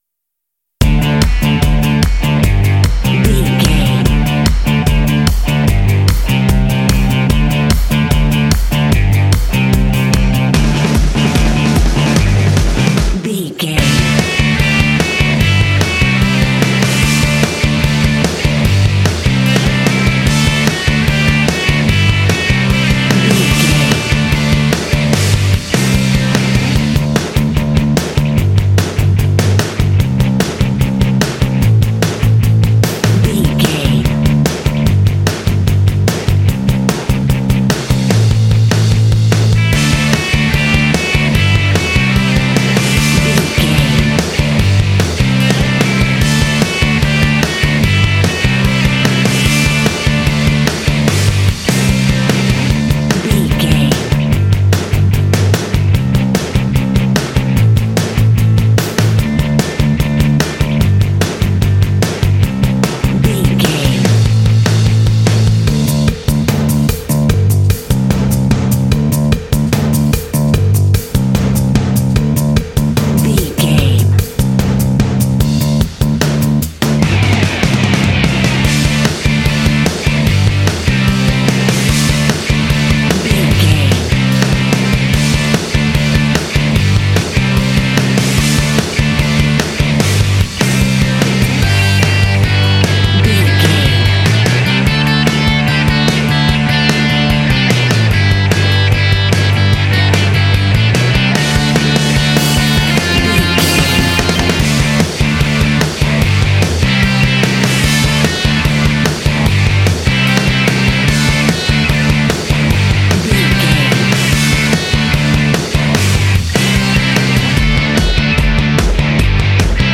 This cute indie track is ideal for action and sports games.
Uplifting
Ionian/Major
D
Fast
happy
energetic
electric guitar
bass guitar
drums
classic rock
alternative rock
indie